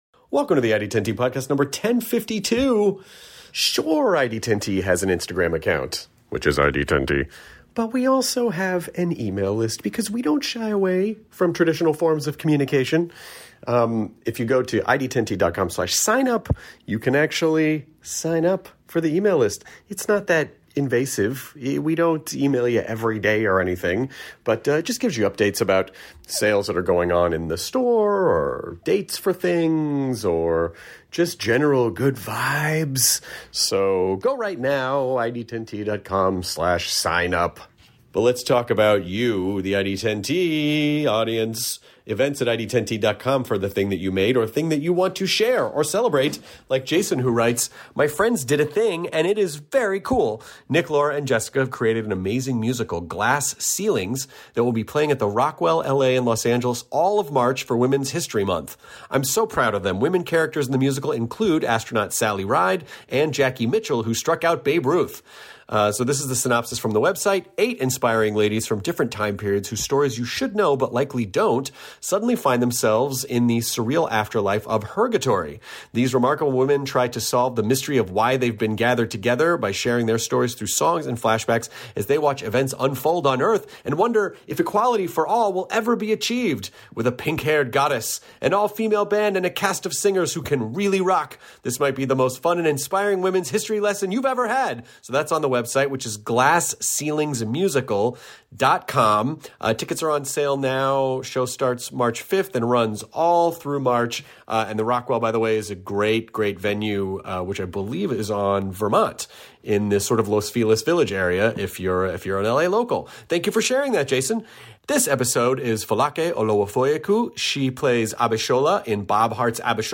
Folake Olowofoyeku (actress, musician, Bob Hearts Abishola) sits down with Chris to talk about how many languages there are in the world, she talks about growing up in Nigeria and they talk about video games they love to play. Folake also talk about her love for playing music, how she got into acting and her show Bob Hearts Abishola on CBS!